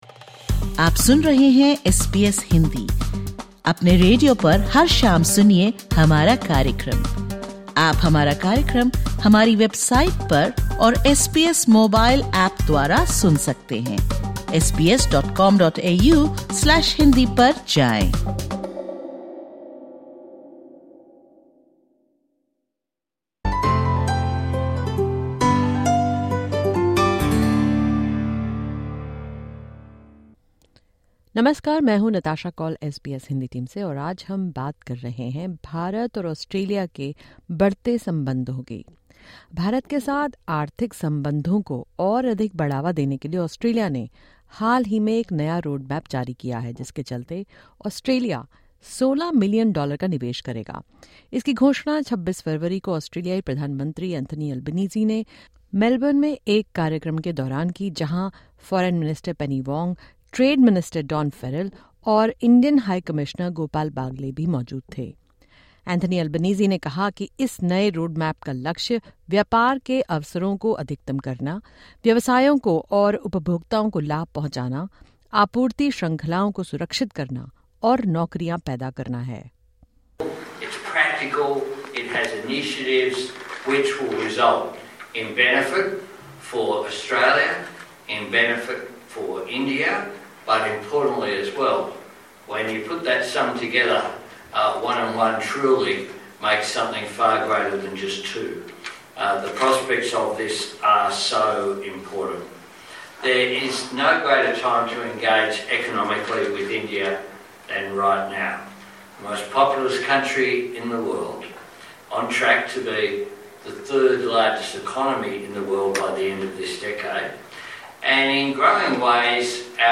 In this podcast, SBS Hindi speaks with Indian High Commissioner Gopal Baglay about the roadmap’s significance for both nations and the ongoing negotiations toward a comprehensive free trade agreement.